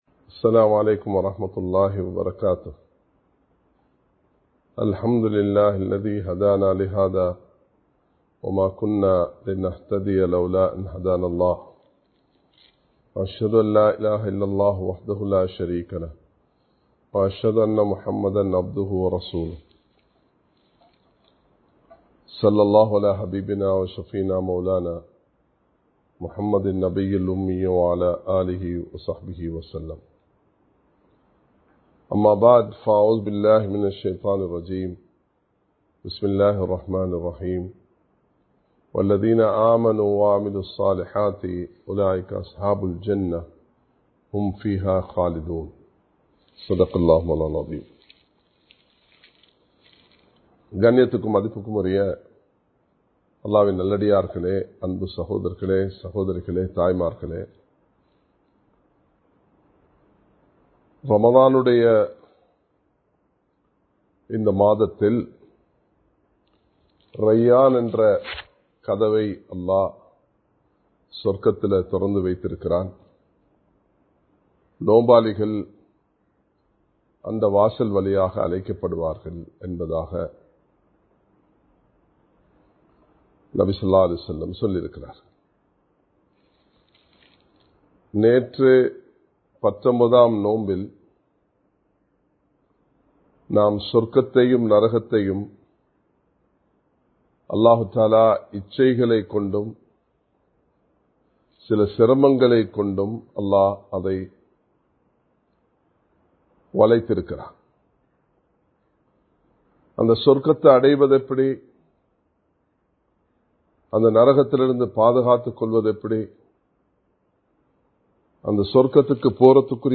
சுவர்க்கத்திற்கான பாதை (The Path to Heaven) | Audio Bayans | All Ceylon Muslim Youth Community | Addalaichenai